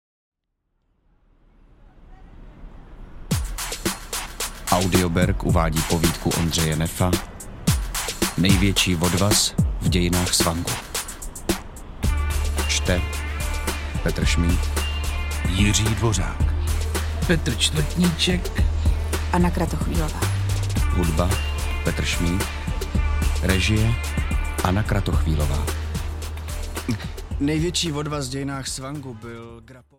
Audiokniha Největší vodvaz v dějinách svangu, kterou napsal Ondřej Neff.
Ukázka z knihy